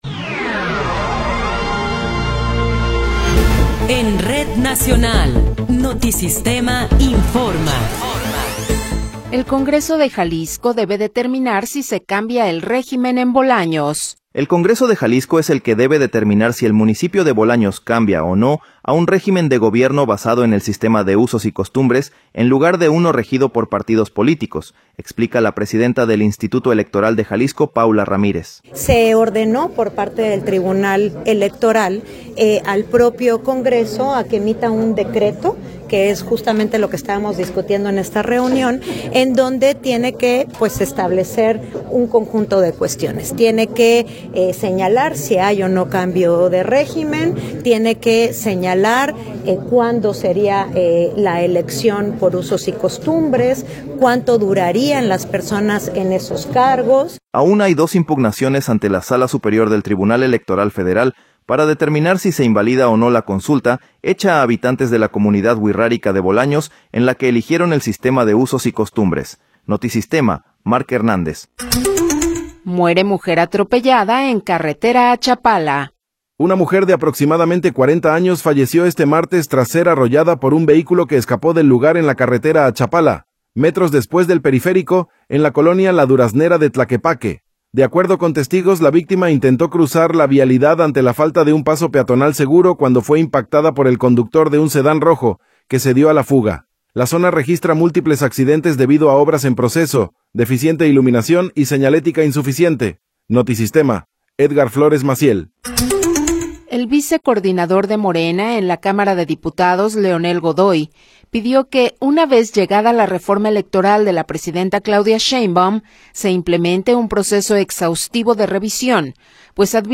Noticiero 15 hrs. – 3 de Marzo de 2026
Resumen informativo Notisistema, la mejor y más completa información cada hora en la hora.